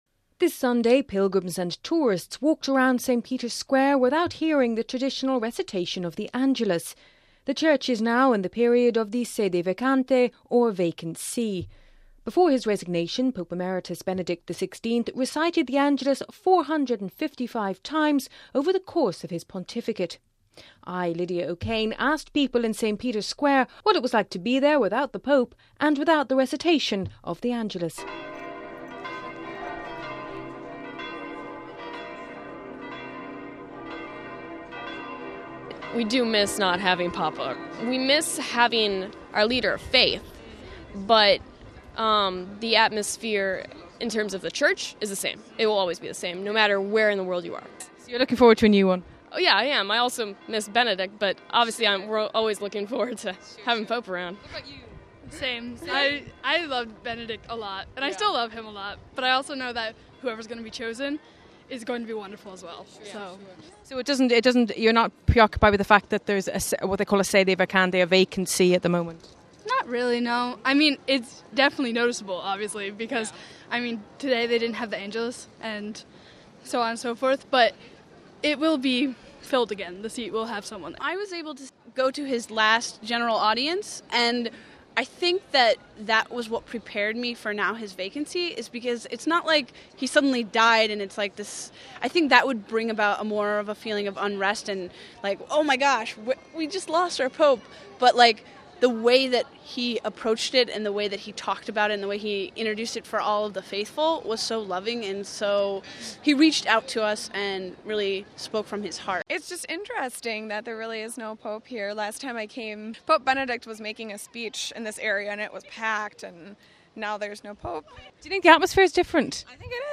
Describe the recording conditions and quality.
(Vatican Radio) This Sunday pilgrims and tourists walked around St Peter’s Square without hearing the traditional recitation of the Angelus by the Pope. The Church is now in the period of the Sede Vacante or Vacant See.